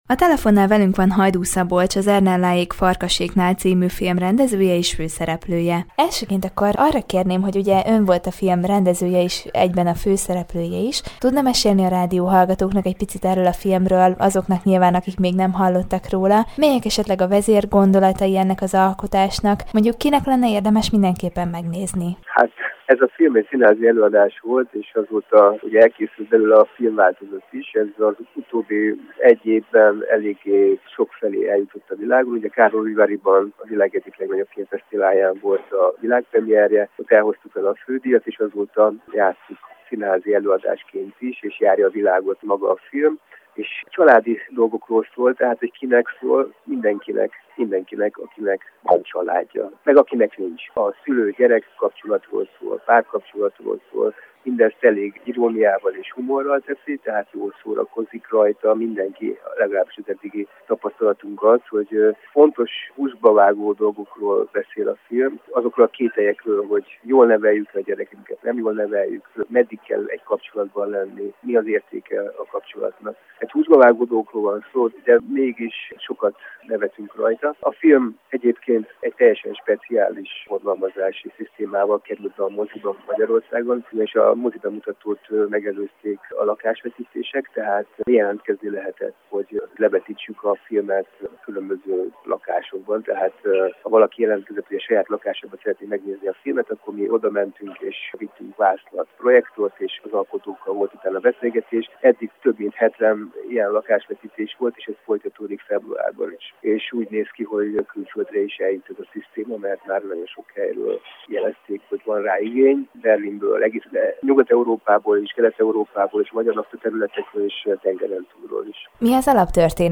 Az Ernelláék Farkaséknál című, Karlovy Vary-ban több díjat nyert filmet nézhették meg az érdeklődők a Gyulai Várszínház Kamaratermében. A film üzenetéről, fogadtatásáról kérdezte tudósítónk Hajdu Szabolcsot, a film rendezőjét és főszereplőjét.